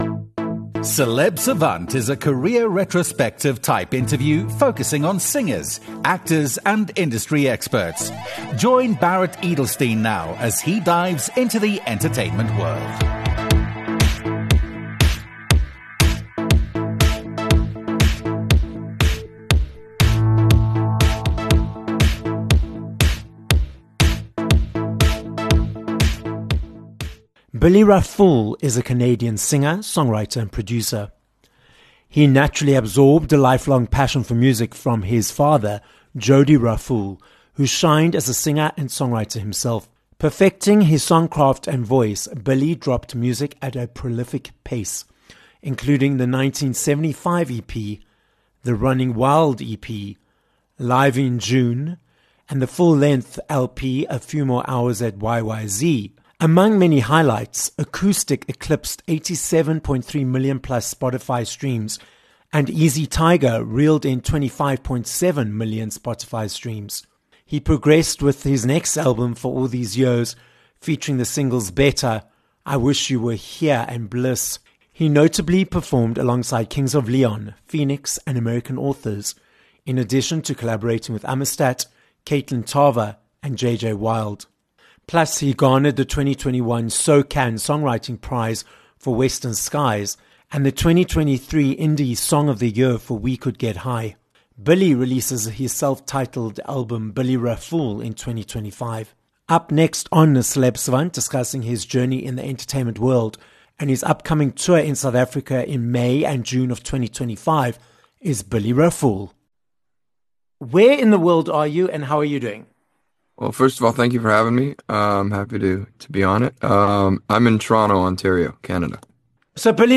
Billy Raffoul - a Canadian singer, songwriter, and producer - joins us on this episode of Celeb Savant.